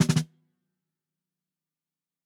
TUNA_SNARE_5.wav